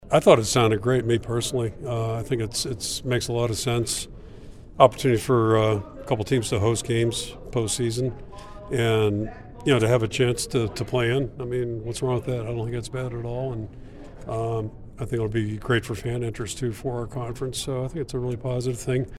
Ferentz made his comments at Big Ten Media days in Las Vegas and like the other coaches in the league praised Petiti's leadership during the rapidly changing landscape of college athletics.